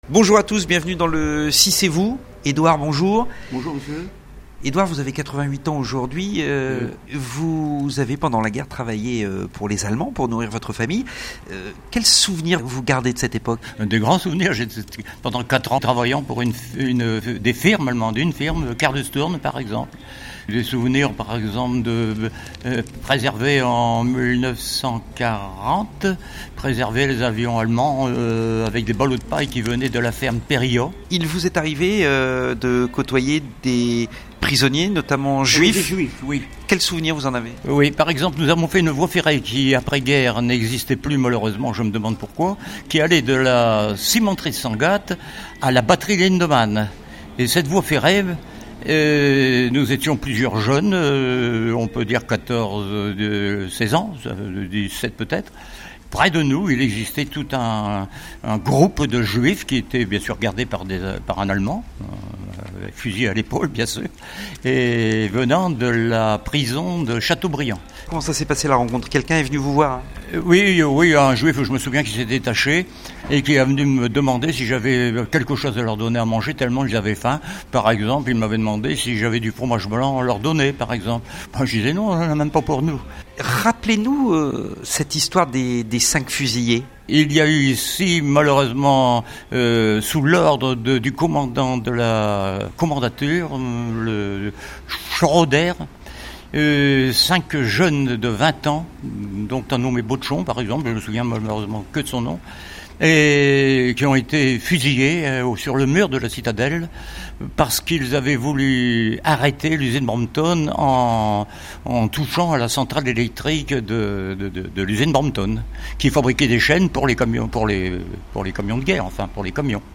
cette année nous célébrons les 70 ans de la fin de la seconde guerre mondiale...voici un témoignage poignant à découvrir sur RADIO 6